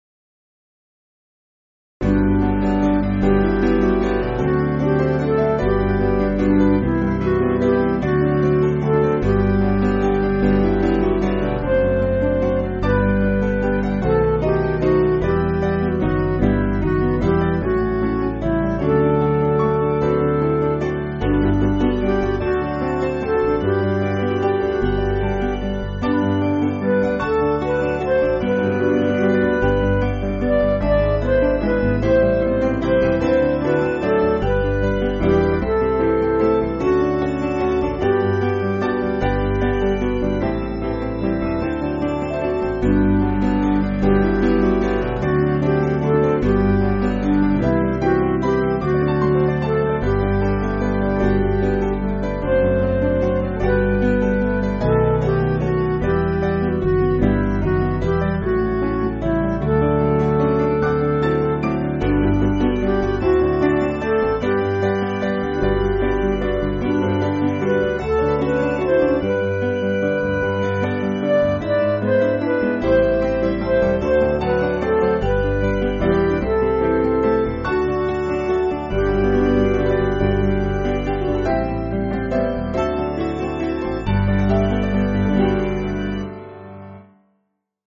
Kid`s club music
Piano & Instrumental